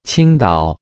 Standard Mandarin
IPA[tɕʰíŋ.tàʊ]